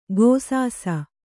♪ gōsāsa